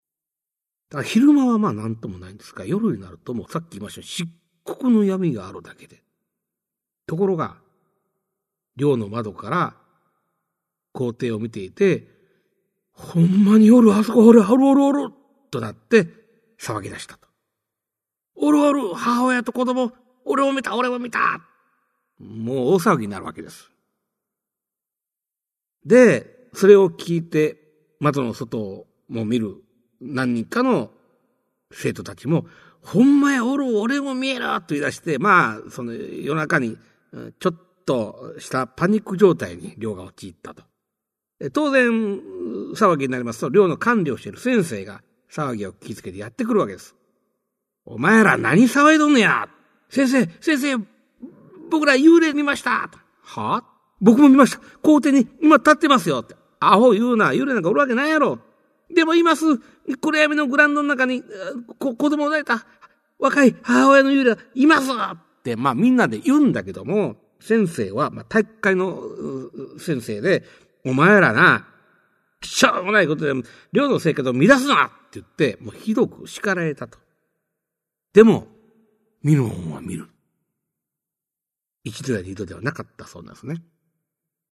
[オーディオブック] 市朗怪全集 四十一
実話系怪談のパイオニア、『新耳袋』シリーズの著者の一人が、語りで送る怪談全集! 1990年代に巻き起こったJホラー・ブームを牽引した実話怪談界の大御所が、満を持して登場する!!